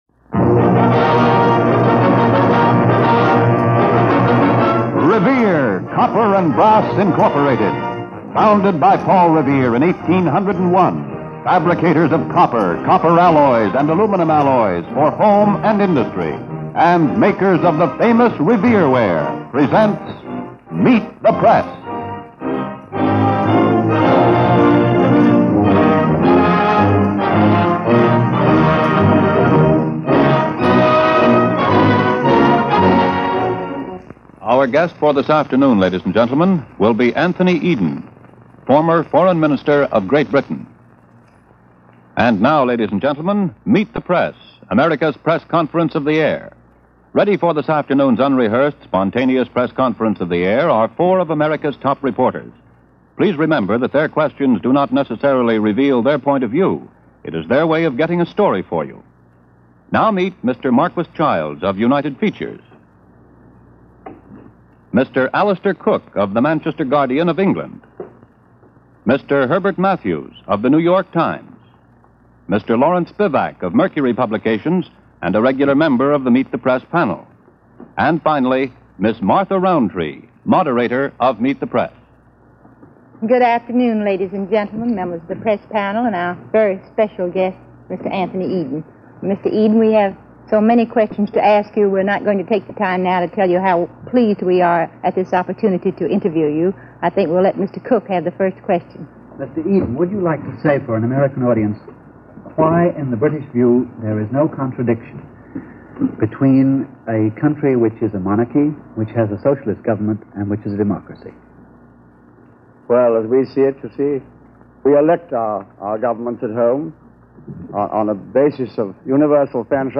The subject of the Middle East and the shift of power, along with the Iranian Oil matter along with the change in government and the Nationalization of Iran‘s Oil Industry under Mossadegh took scant interest on this panel – offering a few short questions towards the end of this interview.